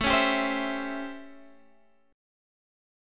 Listen to A7#9 strummed